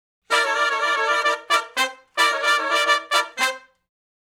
Index of /90_sSampleCDs/Sonic Foundry (Sony Creative Software) - Crimson Blue and Fabulous Horncraft 4 RnB/Horncraft for R&B/Sections/011 Funk Riff
011 Funk Riff (C) har.wav